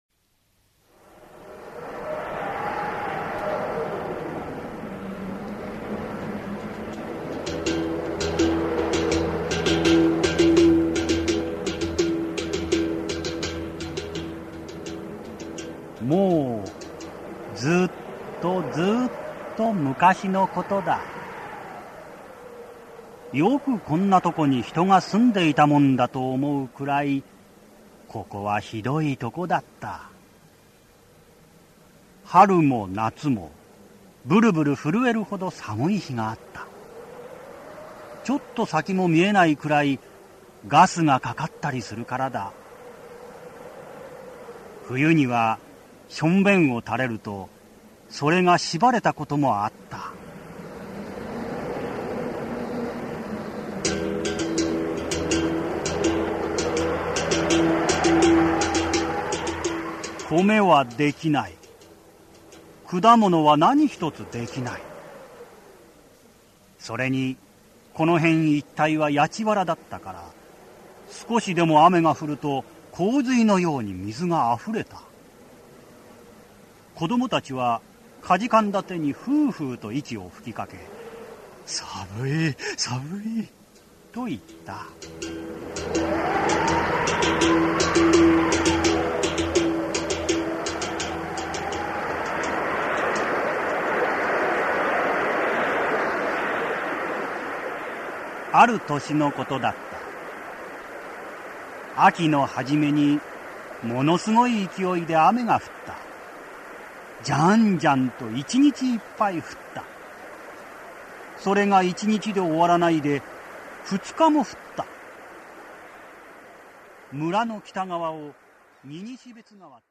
[オーディオブック] ねずみ十ぴき入来